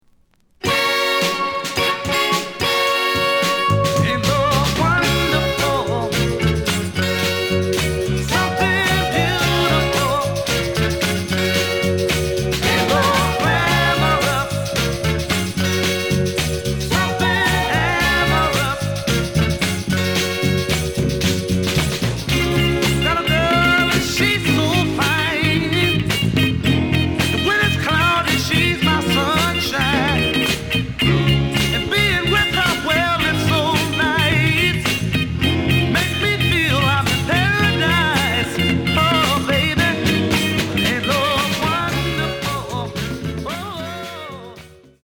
The audio sample is recorded from the actual item.
●Genre: Soul, 60's Soul
Slight damage on both side labels. Plays good.)